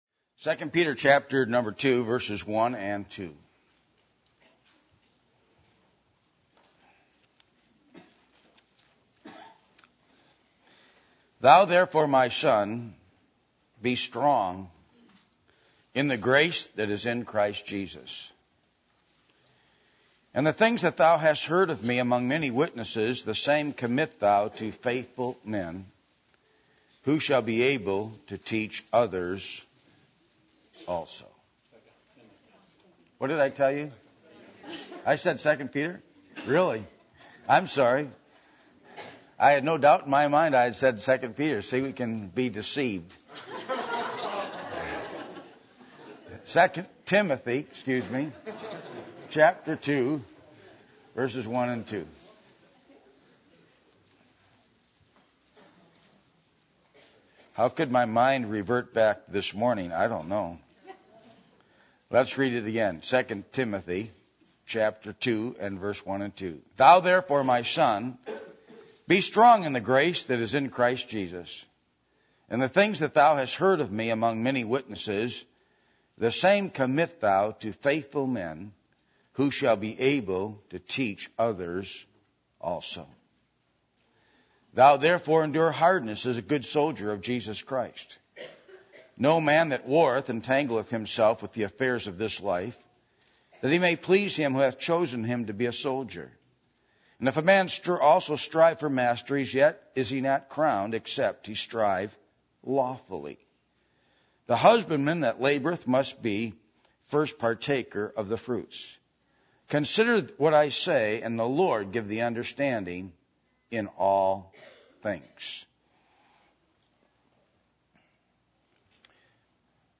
Passage: 2 Timothy 2:1-2 Service Type: Sunday Evening %todo_render% « Communion with the Holy Ghost Baptist Churches